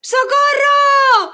Mujer pidiendo ayuda: ¡Socorro! 2
Sonidos: Voz humana